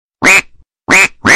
Рингтоны » звуки животных » Кряканье утки